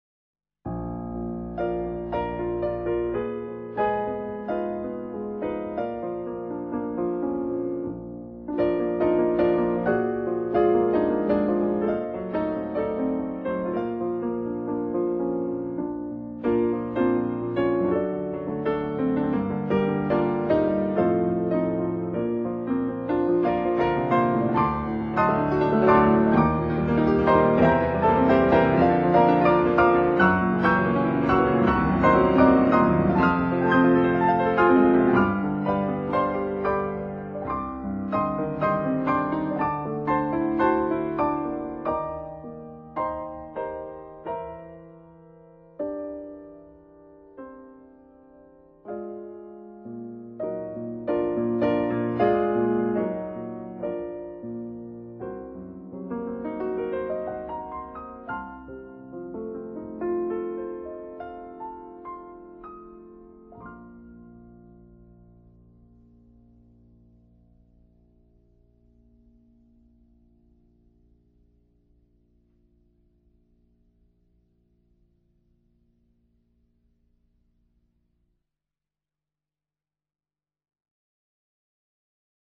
in C minor